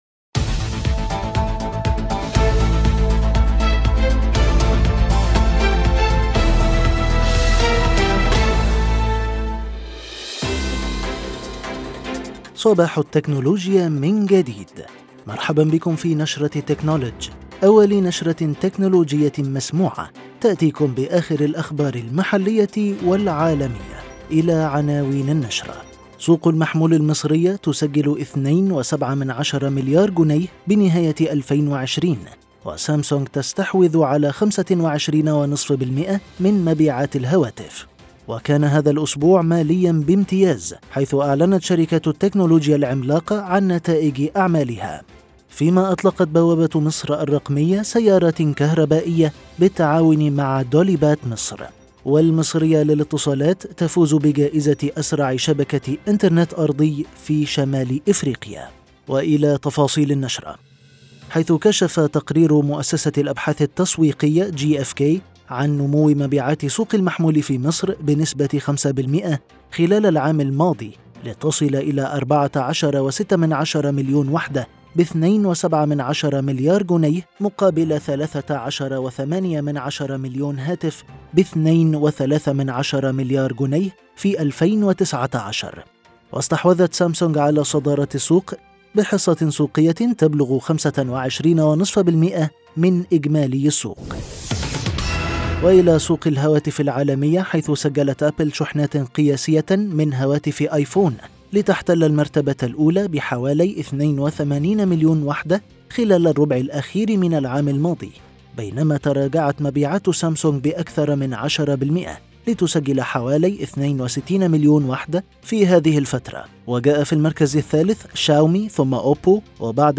نشرة «تكنولدج» المسموعة .. العدد الأول